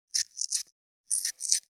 504桂むき,大根の桂むきの音切る,包丁,厨房,台所,野菜切る,咀嚼音,ナイフ,調理音,
効果音厨房/台所/レストラン/kitchen食材